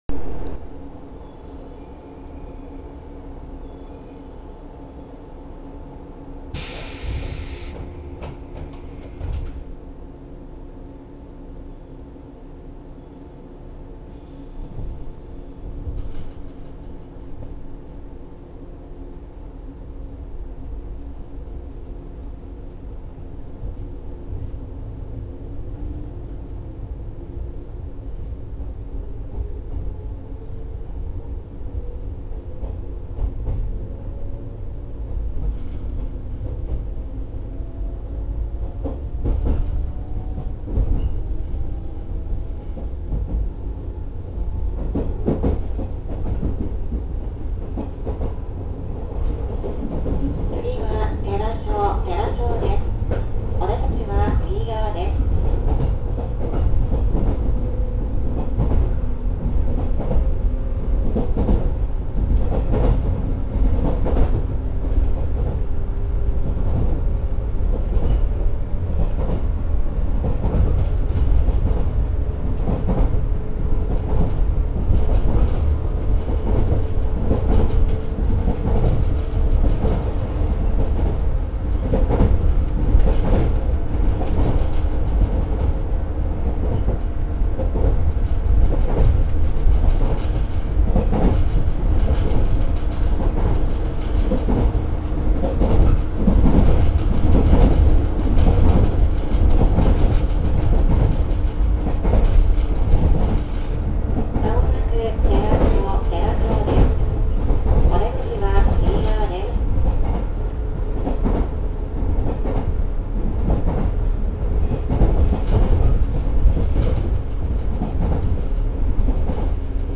〜車両の音〜
・113系走行音
【草津線】甲南→寺庄（2分45秒：896KB）
懐かしの抵抗制御です。